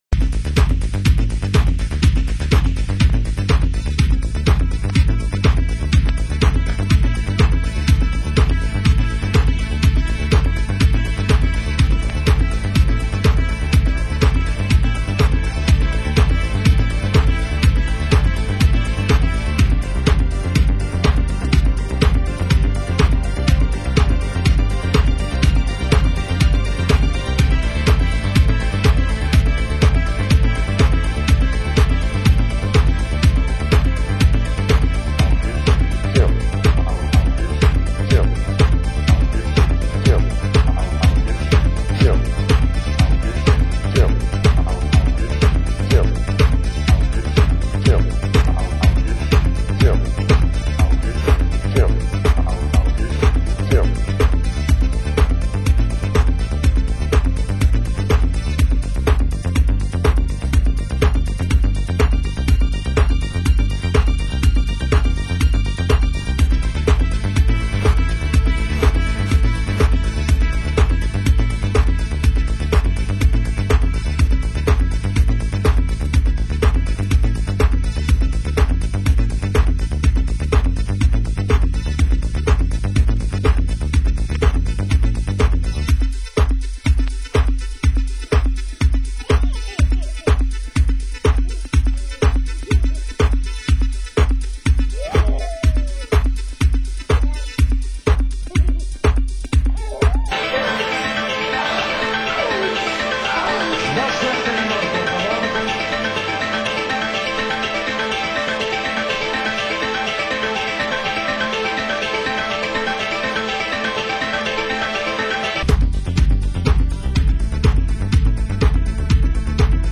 Genre: New Beat